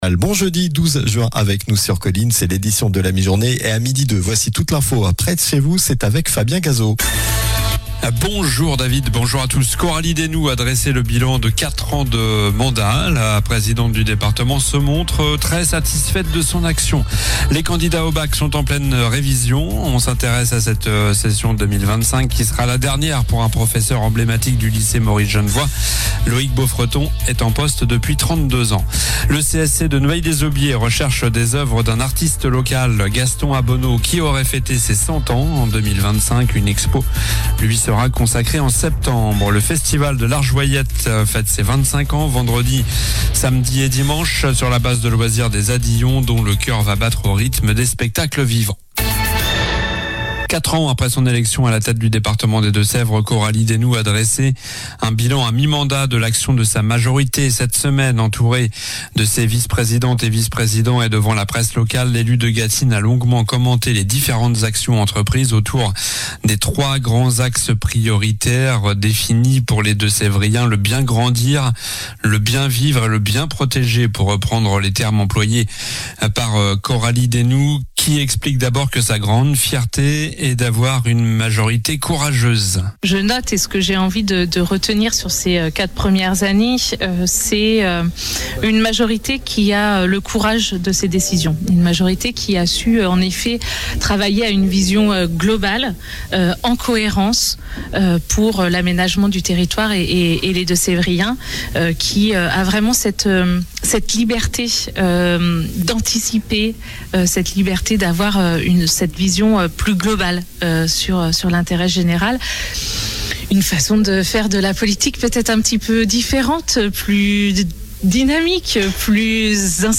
Journal du jeudi 12 juin (midi)